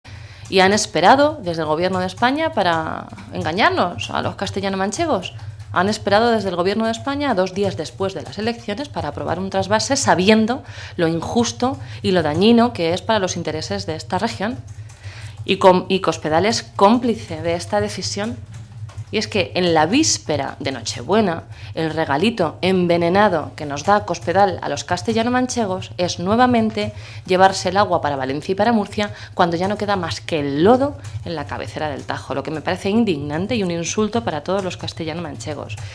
La portavoz del Grupo Parlamentario socialista en las Cortes, Blanca Fernández, ha lamentado que el gobierno de España haya aprobado un nuevo trasvase de 6 hectómetros cúbicos del Tajo al Levante y más cuando los embalses de cabecera están “peor que nunca, en mínimos históricos”, con unas reservas por debajo del 13%.
Cortes de audio de la rueda de prensa